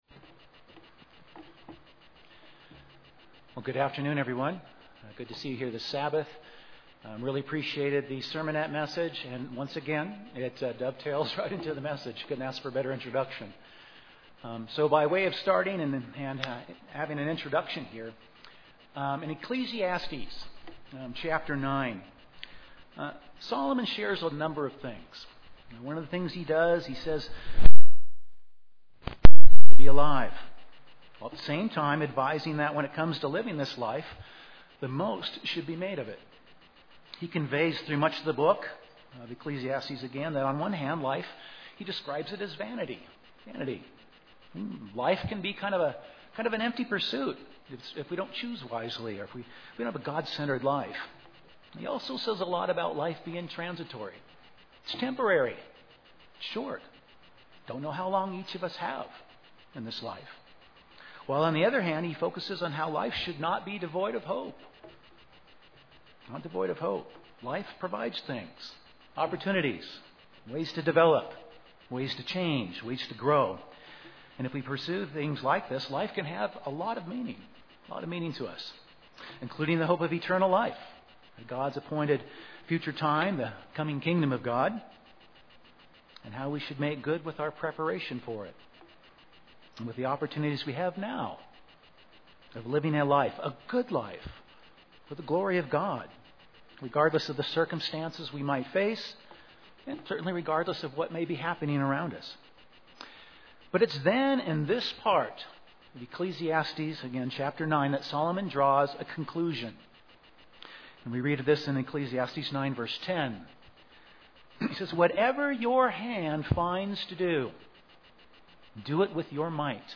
The sermon focuses on the broad concept of work found in scripture, how God can help and direct us with it, and to answer the question, does God have expectations with regards to our work?
Given in Colorado Springs, CO